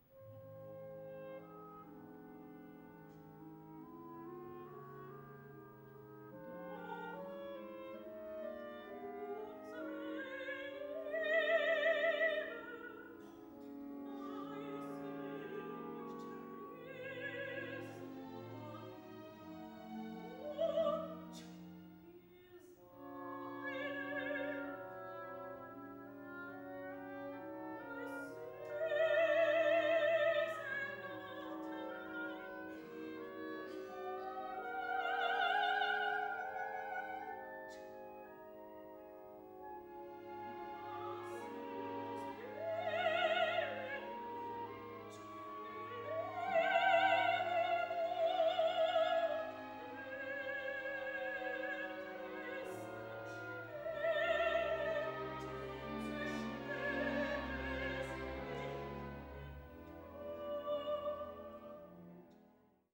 OPER Kurze Ausschnitte (dafür in WAV-Qualität) aus TRISTAN UND ISOLDE: I. Akt Wie lachend Sie mir Lieder singen II.